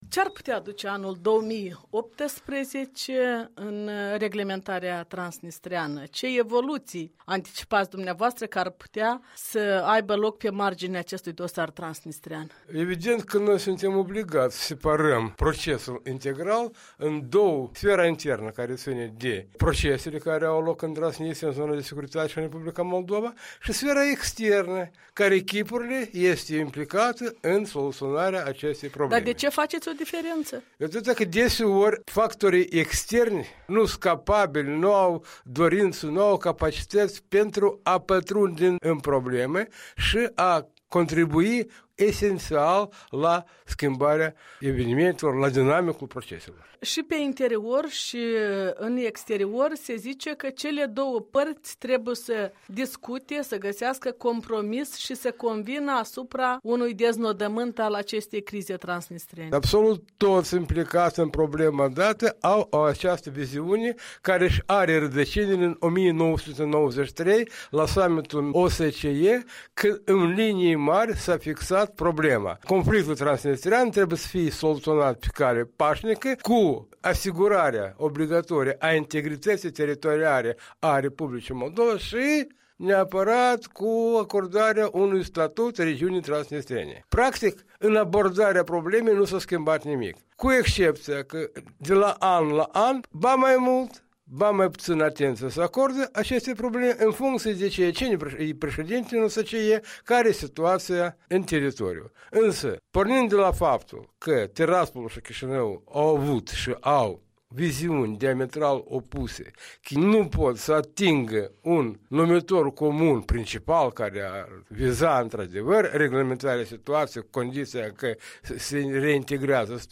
Interviu cu fostul membru al delegației moldovene în Comisia Unificată de Control, despre ultimele evoluții în reglementarea transnistreană.